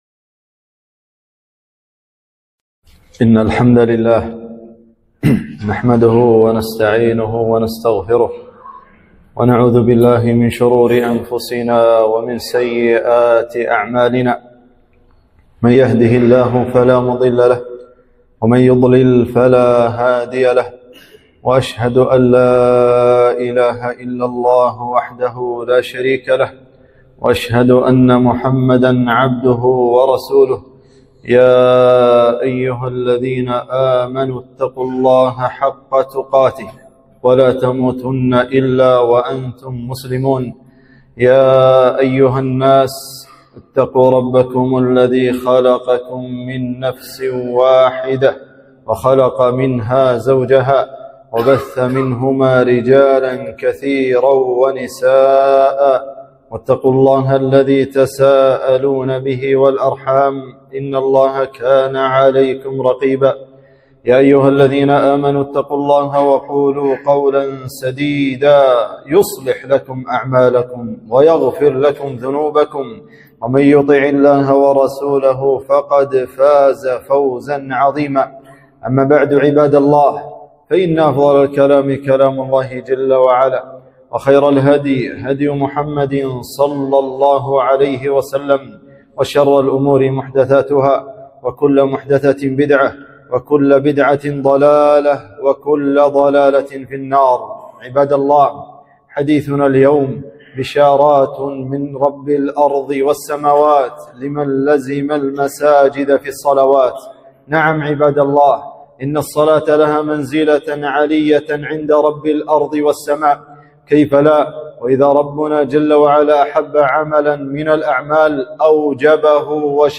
خطبة - بشارات لمن لزم المساجد في الصلوات